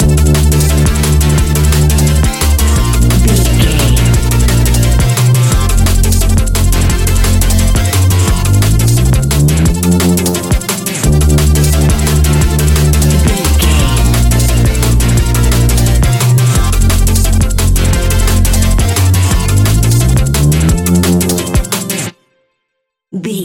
Ionian/Major
A♯
electronic
dance
techno
trance
synths
synthwave
instrumentals